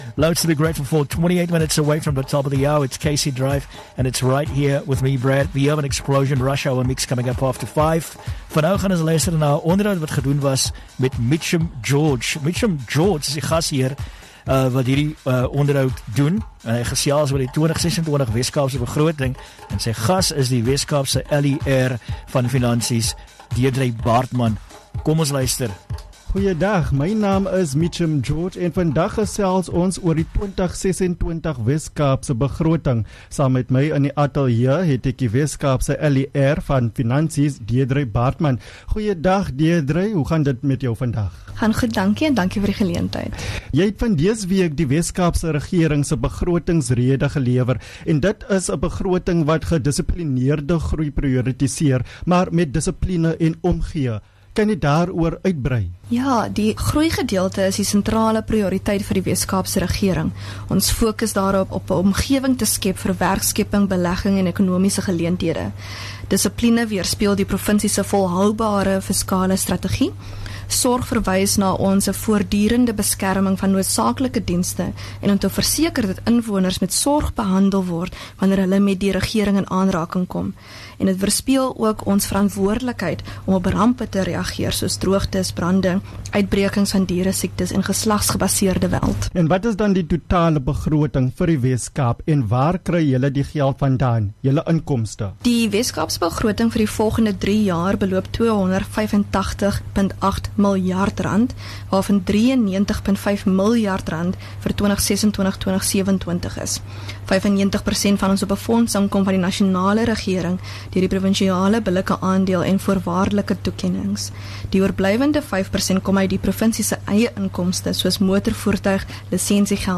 Deidré Baartman, MEC for Finance, Economic Opportunities and Tourism in the Western Cape, in conversation about the 2026 Western Cape Budget.